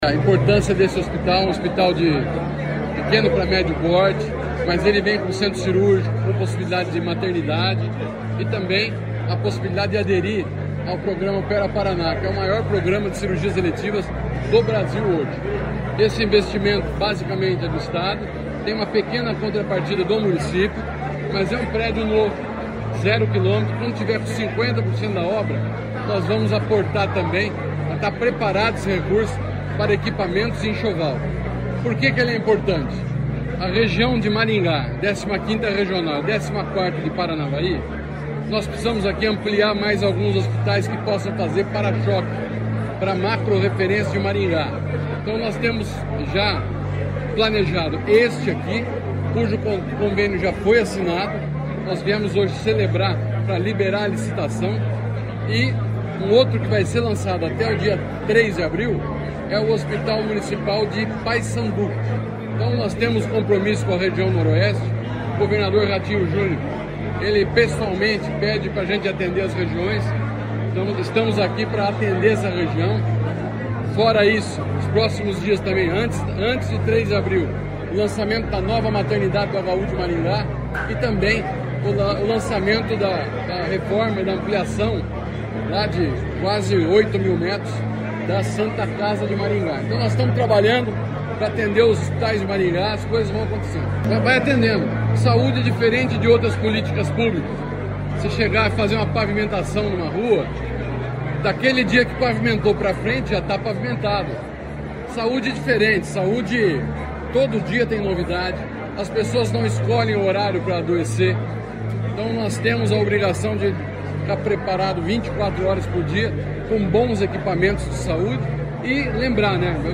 Sonora do secretário Estadual da Saúde, Beto Preto, sobre o anúncio do novo hospital de Nova Esperança